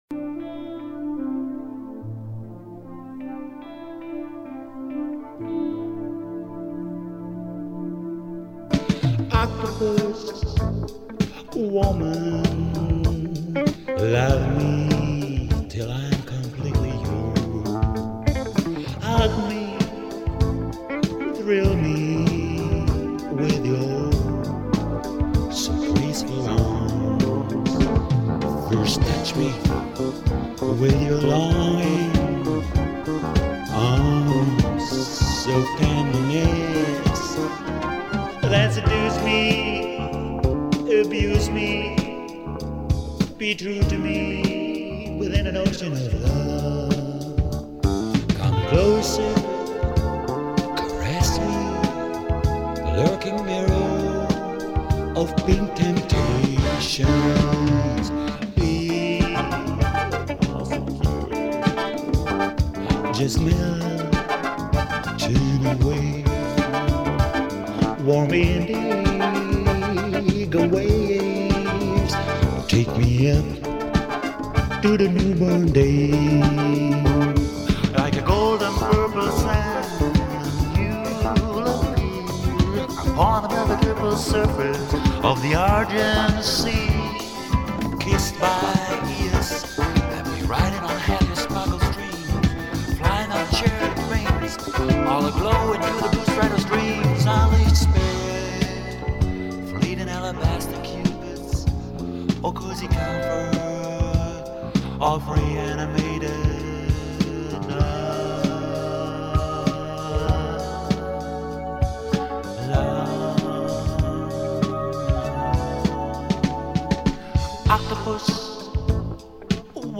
Rock-Oratorio